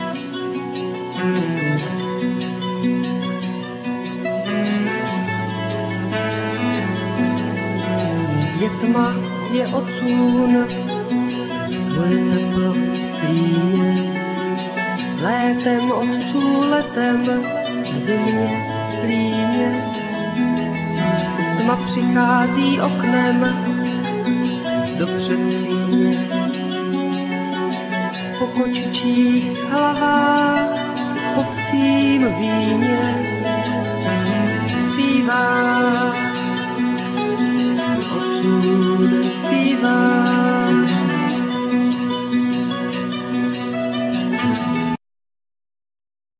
Vocal,Violin,Okarina
Mandolin,Guitar
Saxophone,ClarinetXylophone,Bonga
Cello,Violin